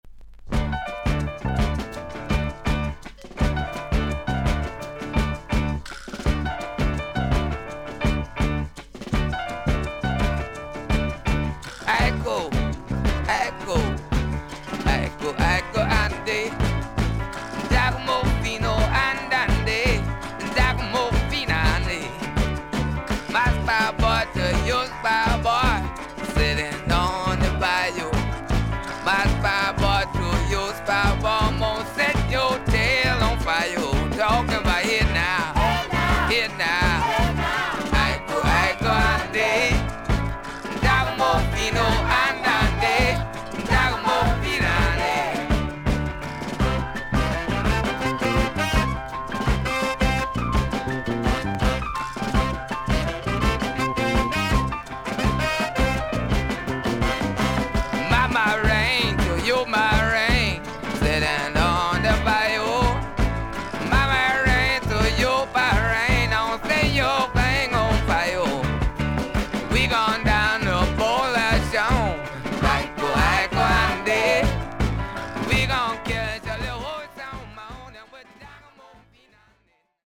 少々軽いパチノイズの箇所あり。少々サーフィス・ノイズあり。クリアな音です。
ロック/R&Bピアニスト。ニュー・オーリンズR&Bの名曲を多数カヴァーした名盤。